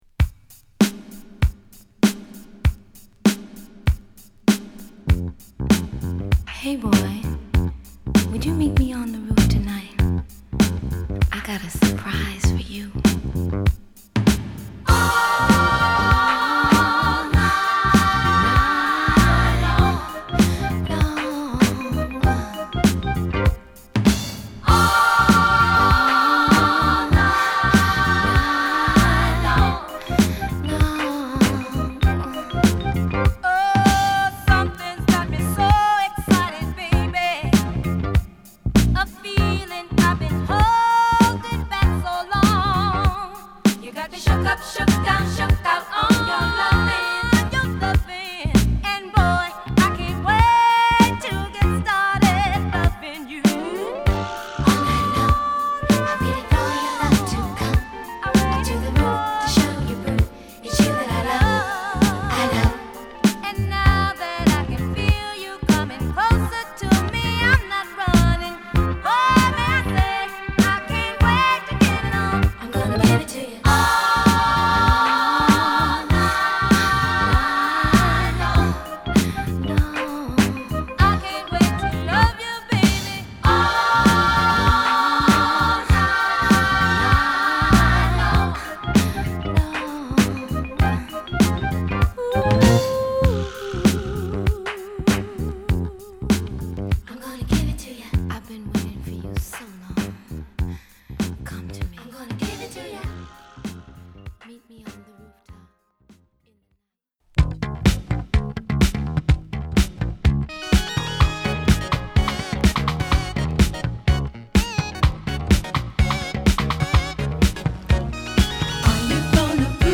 メロウでリラックスした曲を披露